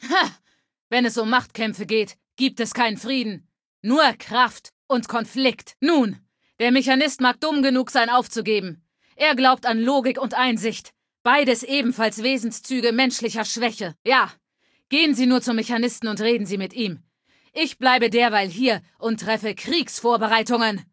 Datei:Femaleadult01default ms02 ms02superheroexplain3 0003c8c4.ogg
Fallout 3: Audiodialoge